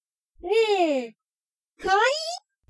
• 音声のピッチ加工（女声（犯罪者声）っぽくする）
ねぇ、かわいい？（僕の声のボイチェン）